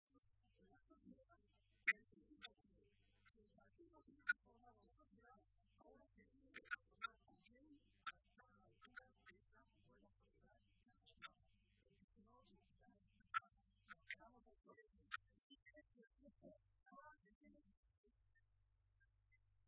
“Hemos trasformado más la sociedad que el propio partido y el partido que ha trasformado la sociedad ahora se tiene también que trasformar para estar a la altura de esa nueva sociedad”, señaló Barreda durante su intervención en la tradicional comida navideña de los socialistas de la provincia de Toledo a la que asistieron más de 1.000 personas.
Intervención de Barreda